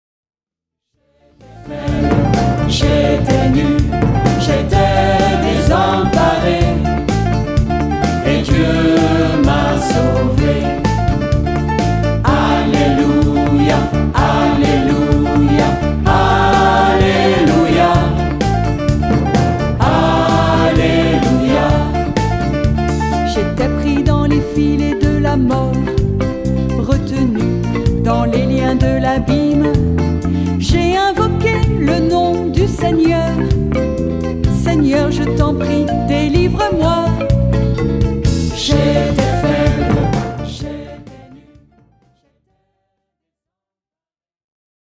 Utilisant des arrangements très colorés
mélodies simples